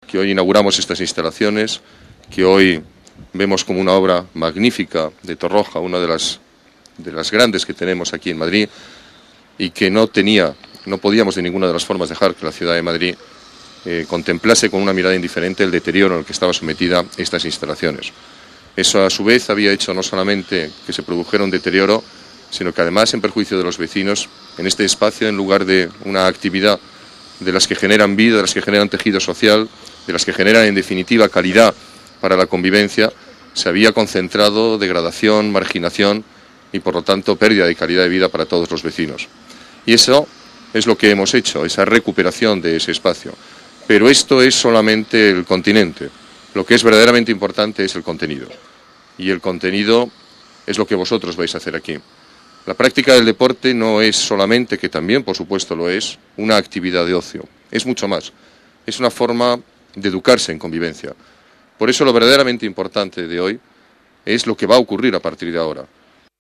Nueva ventana:El alcalde resalta la importancia de la actividad deportiva durante la inauguración de la nueva instalación construida en el antiguo canódromo de Carabanchel